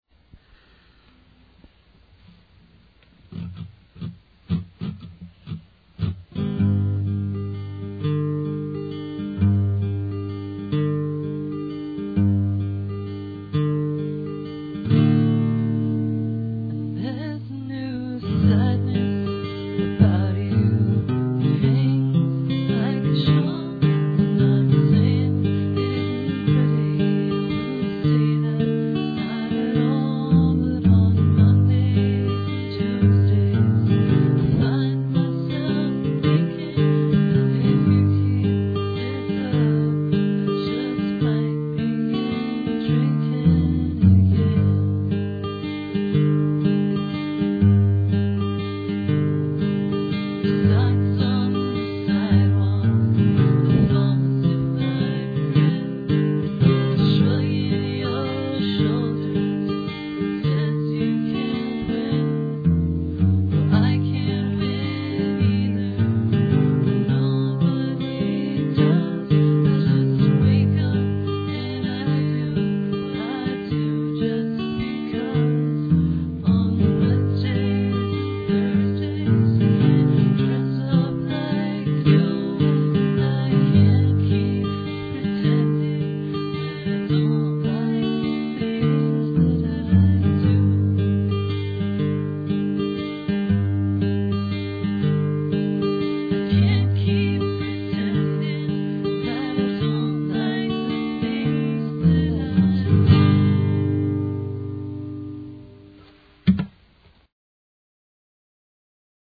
Painstakingly recorded to normal bias generic brand audiotape